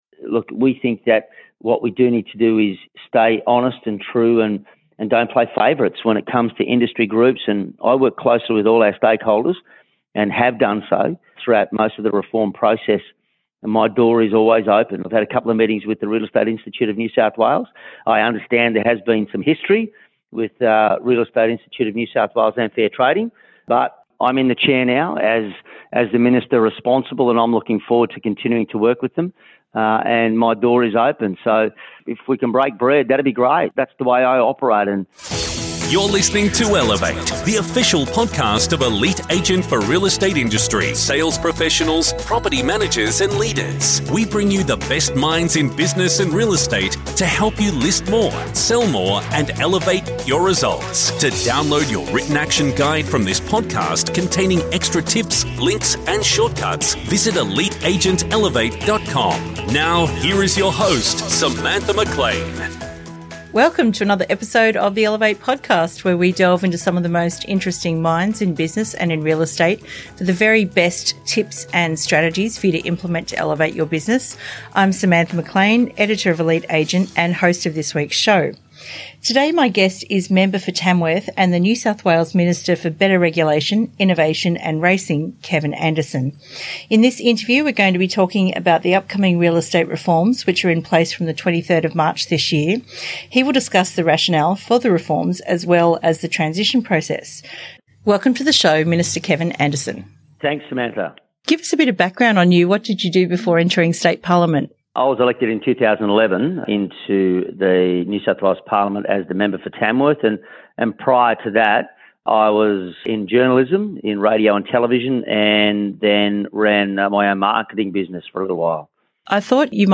This interview was recorded on Tuesday 18/2 and since then a couple of significant changes have taken place: